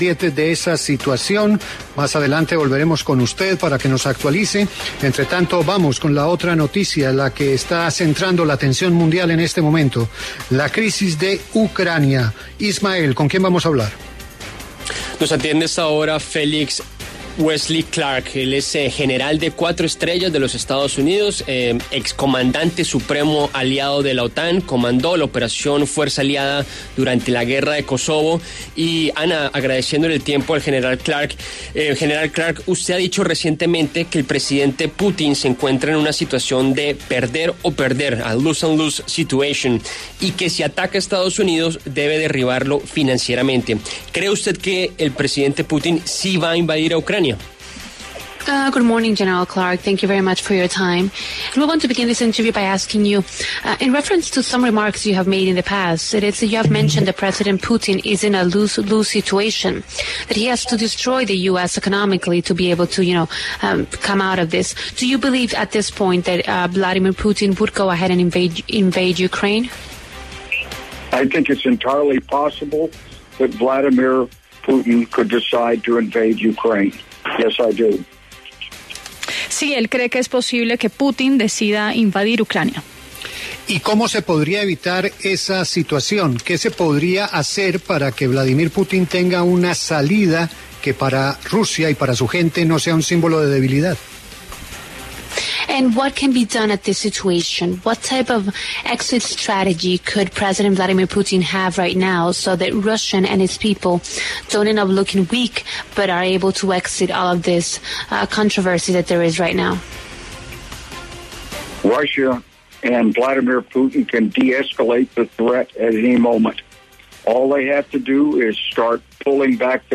Wesley Clark, general retirado de 4 estrellas de Estados Unidos y excomandante supremo aliado de la OTAN, habló en La W sobre la crisis entre Rusia y Ucrania.